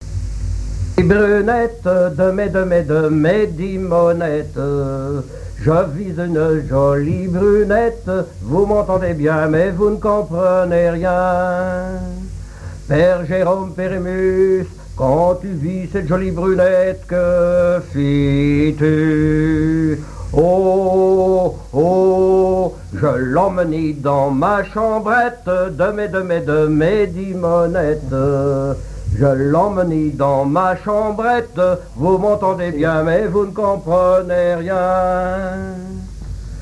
Mémoires et Patrimoines vivants - RaddO est une base de données d'archives iconographiques et sonores.
Genre laisse
Pièce musicale inédite